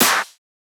TS Clap_2.wav